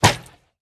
Minecraft Version Minecraft Version latest Latest Release | Latest Snapshot latest / assets / minecraft / sounds / item / crossbow / shoot1.ogg Compare With Compare With Latest Release | Latest Snapshot
shoot1.ogg